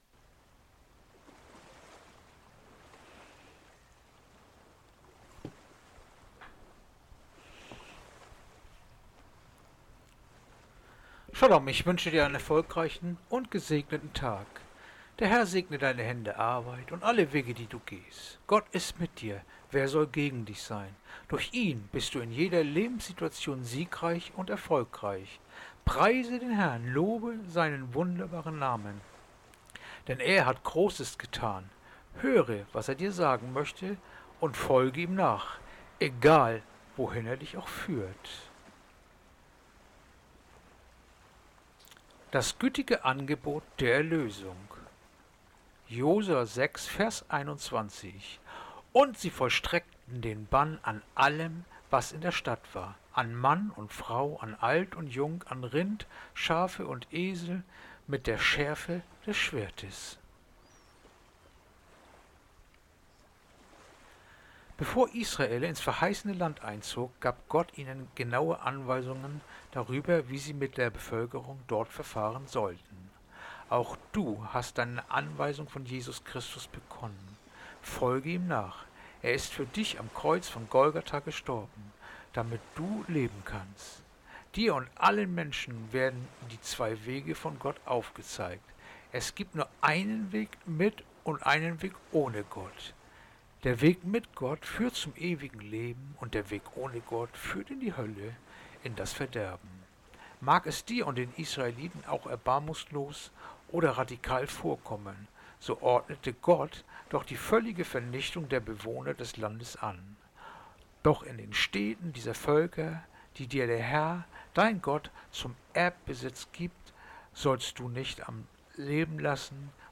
Andacht-vom-18.-Februar-Josua-6-21.mp3